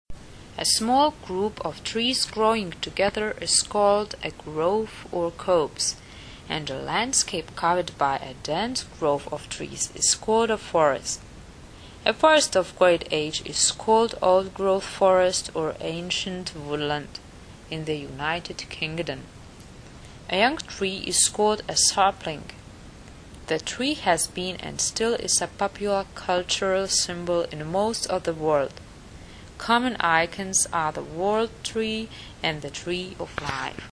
Nahrávka výslovnosti (*.MP3 soubor):